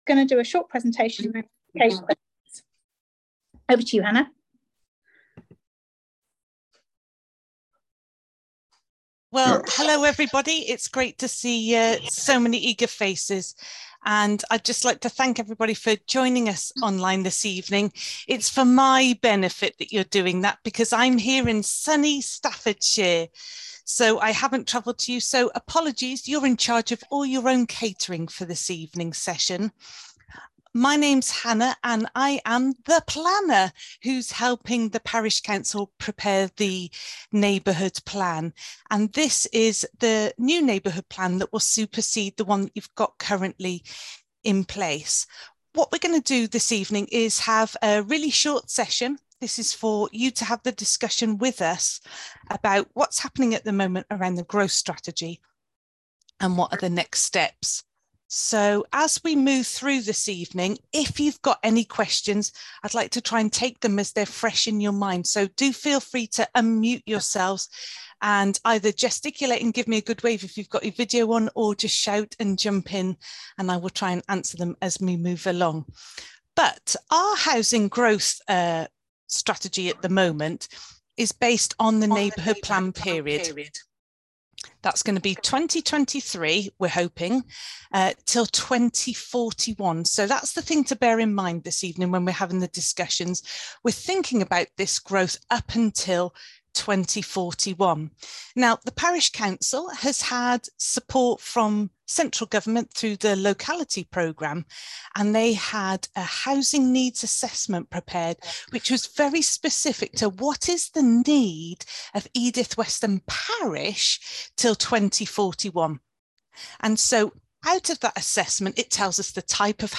We would like to invite you to an open meeting to update you on progress on the Neighbourhood Plan.